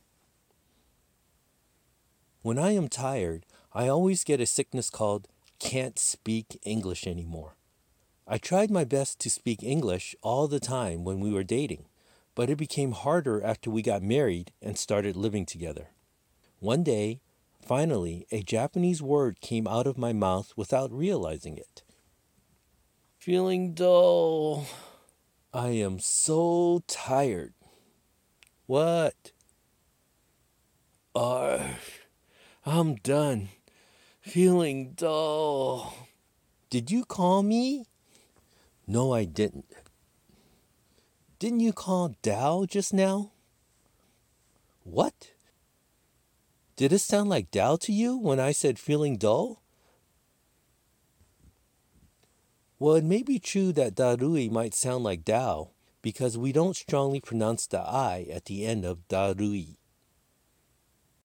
英訳全文をネイティブの発音で聴けるオーディオ付き
ネイティブの発音オーディオ：